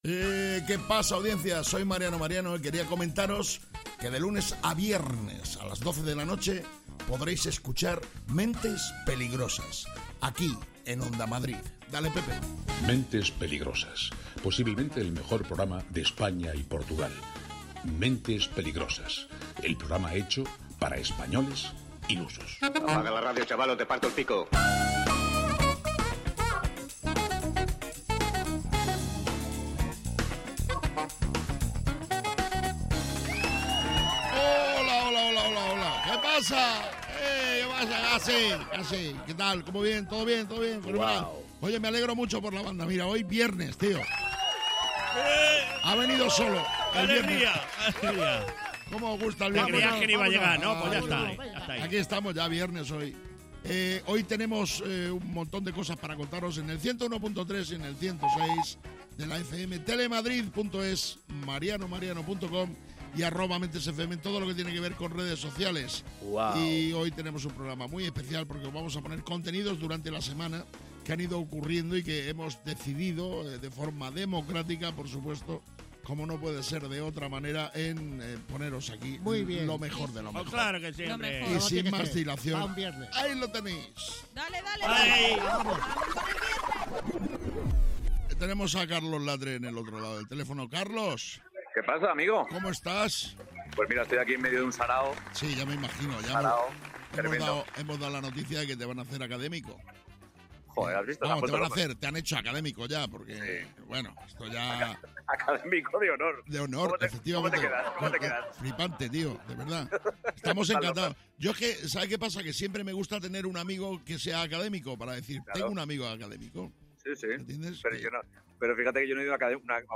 ¿Es un programa de humor?